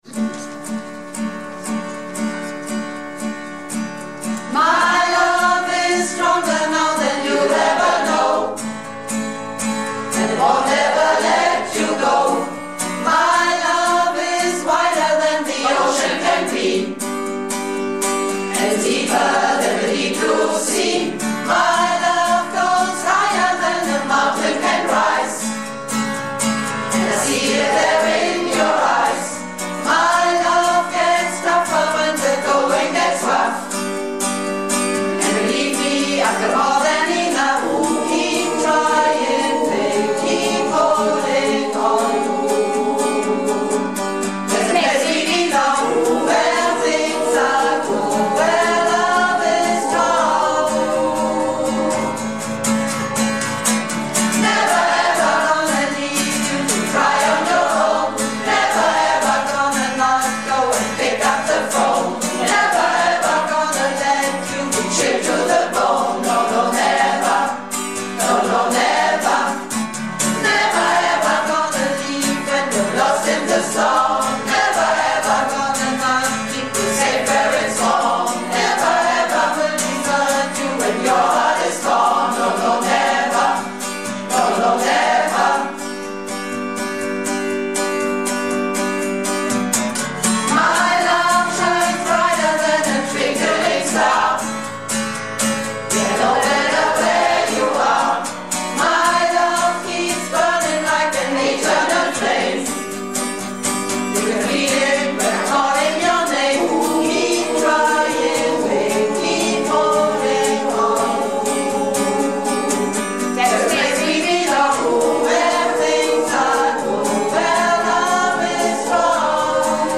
Malle Diven - Chorwochenende (20.01.19)